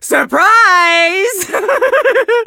chester_ulti_vo_01.ogg